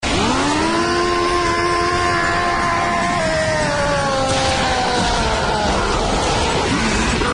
Eren Titan Roar Sound Effect Free Download
Eren Titan Roar